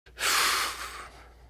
Мужчина выдыхает дым сигарет из легких через рот